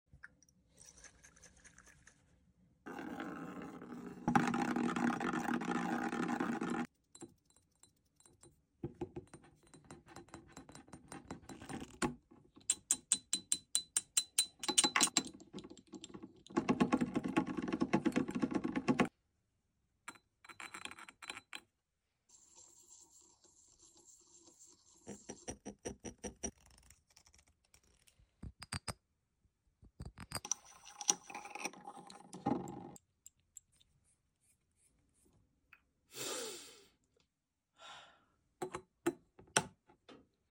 Versace Dylan Blue ASMR sound effects free download